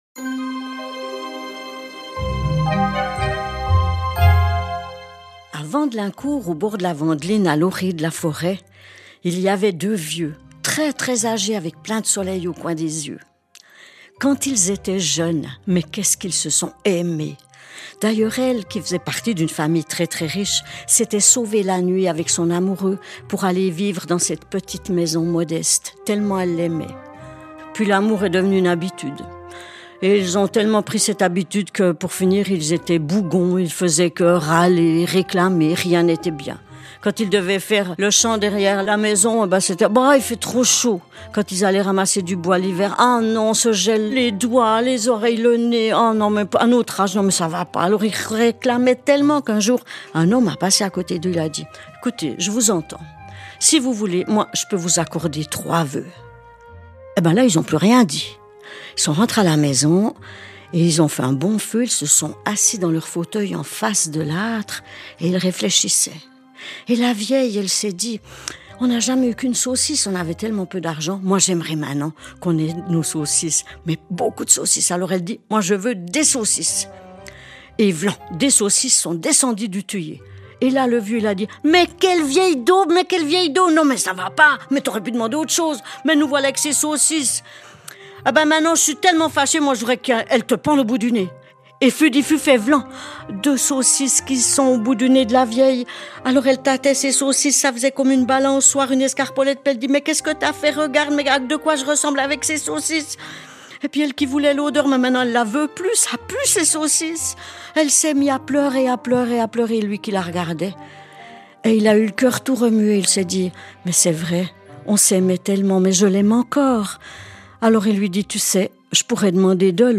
Les membre de l’Association des conteurs et conteuses du Jura nous racontent ces récits issus des ouvrages « Contes et légendes du Jura » ainsi que « La princesse, le berger et le revenant » et enfin  « Vouivres, sorcières, grimoires et loups-garous » parus aux éditions Alphil.